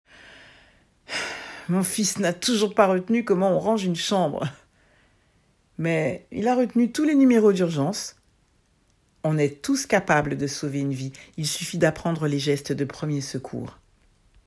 25 - 60 ans - Soprano